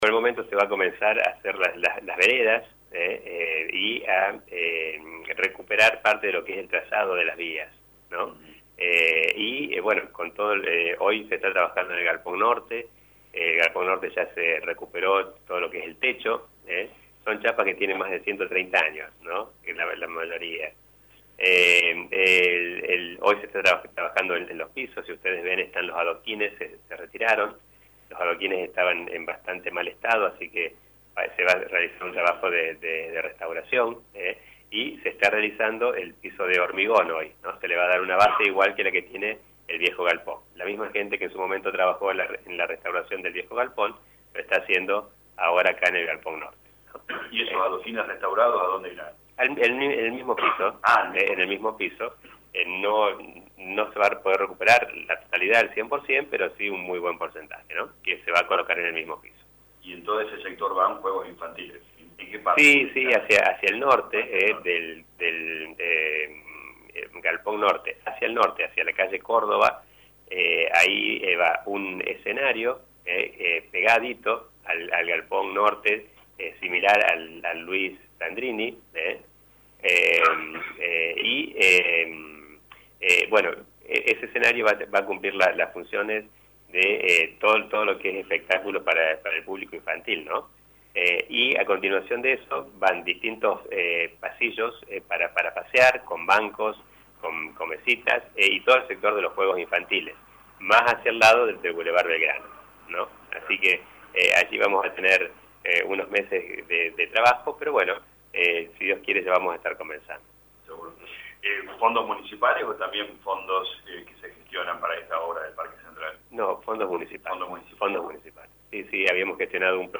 En diálogo con LA RADIO 102.9 FM el intendente Municipal Dr. Gustavo Tevez contó que continúan trabajado en forma conjunta con Ferrocarriles Argentinos para traer a la ciudad diferentes atractivos.